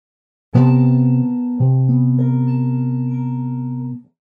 ES4]; da un'analisi successiva ci rendiamo conto che in questo caso abbiamo formato un accordo di C-maj7, cioè un accordo minore con la settima maggiore, ma nulla ci vieta di formare accordi bizzarri o addirittura inqualificabili altrimenti: si pensi a una successione di tre intervalli di settima maggiore, sempre partendo dal C; avremmo queste note: C, B, Bb, A [